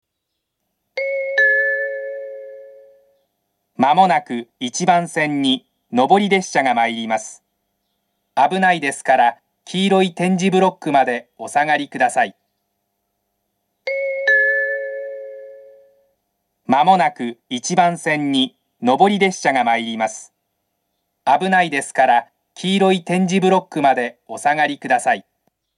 １番線接近放送 上り副線です。